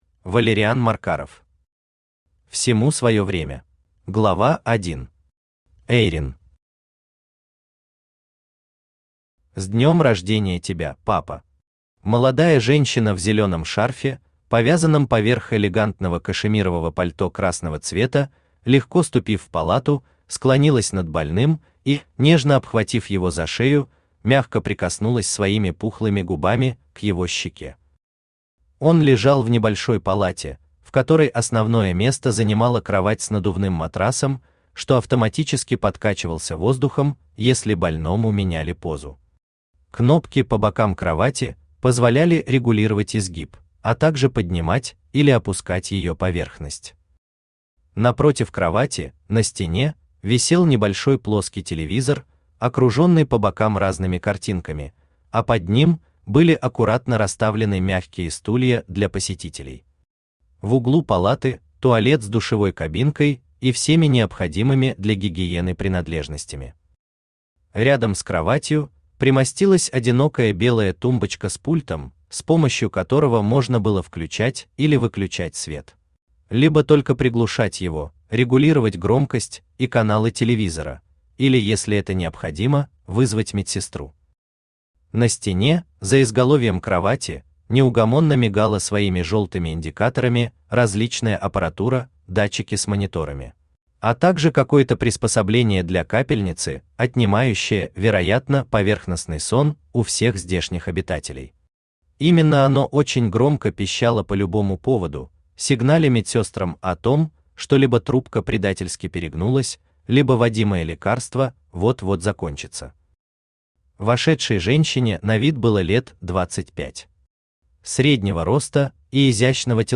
Аудиокнига Всему свое время | Библиотека аудиокниг
Aудиокнига Всему свое время Автор Валериан Маркаров Читает аудиокнигу Авточтец ЛитРес.